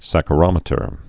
(săkə-rŏmĭ-tər)